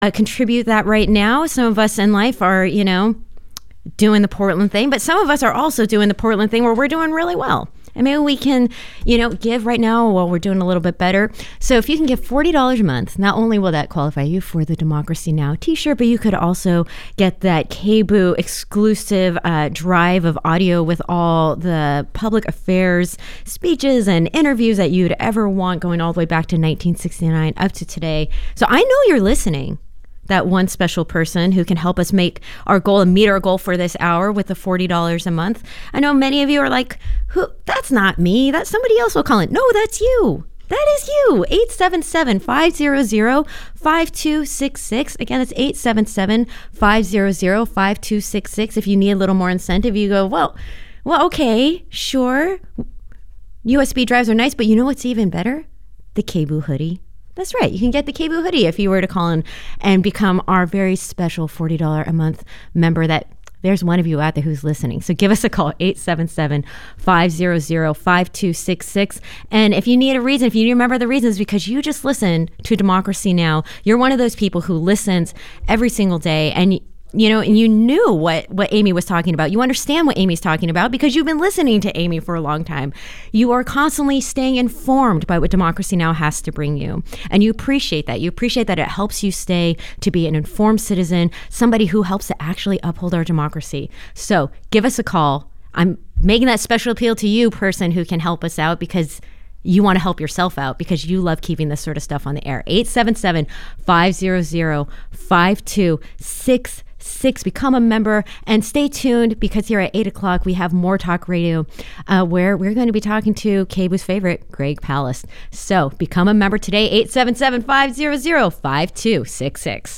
More Talk Radio